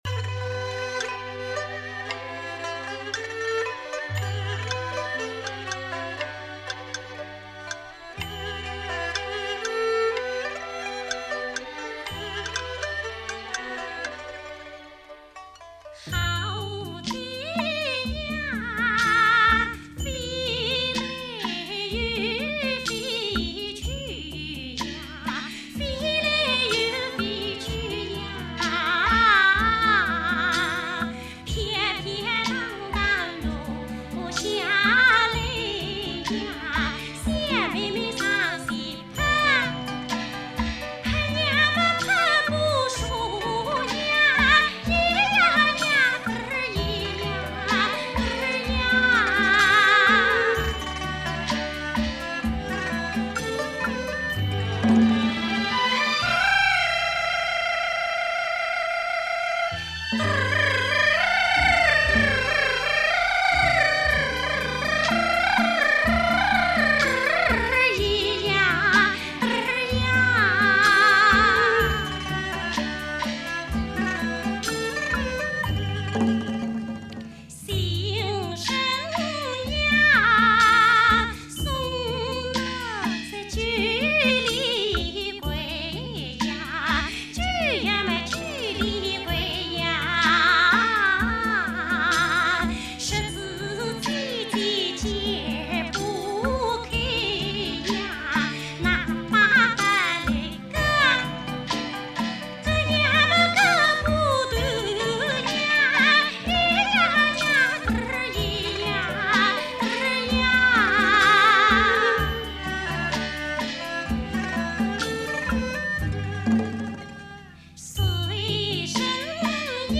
珍贵的歌唱家巅峰时期的录音版本 经典的民歌精选